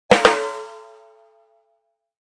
Descarga de Sonidos mp3 Gratis: bateria 3.